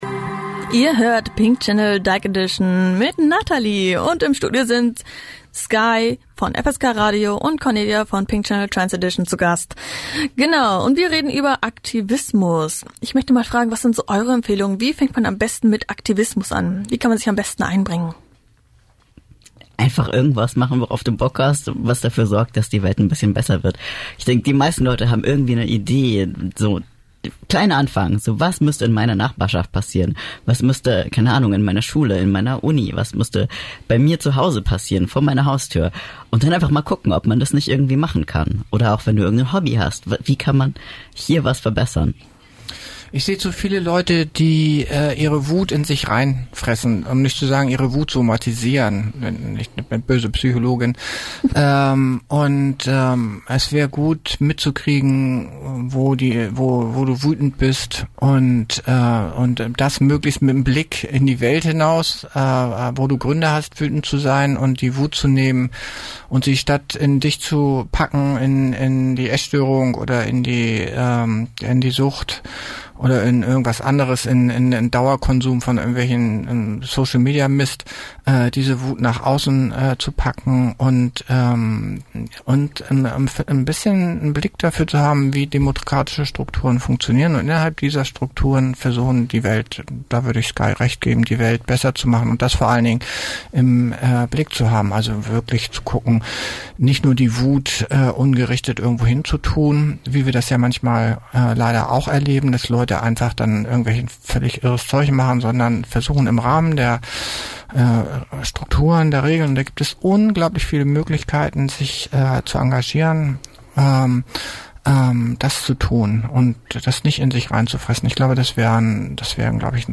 Im letzten Gesprächsteil geht es um Einstieg und Umgang mit Überforderung.